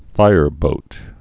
(fīrbōt)